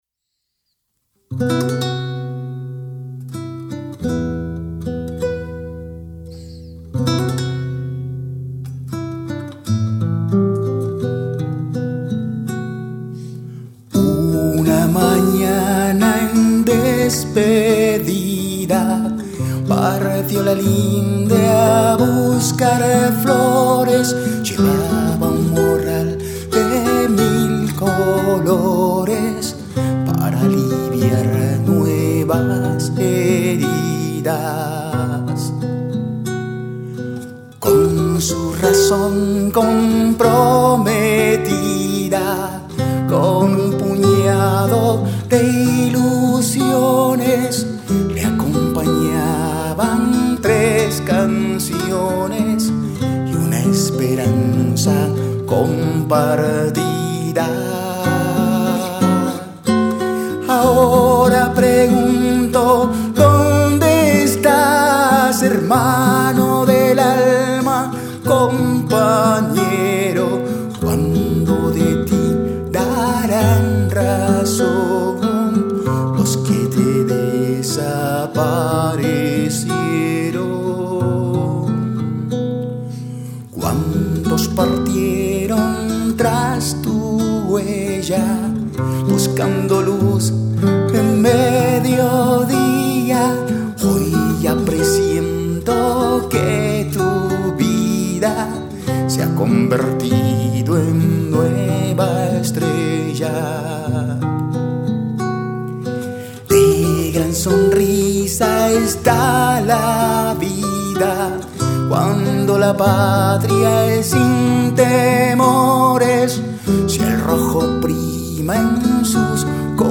voz y guitarra.
arreglos y guitarra.